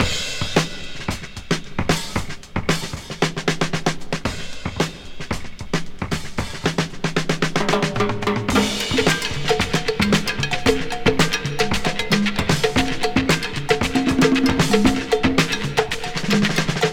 • 113 Bpm Breakbeat Sample G Key.wav
Free breakbeat sample - kick tuned to the G note.
113-bpm-breakbeat-sample-g-key-fSu.wav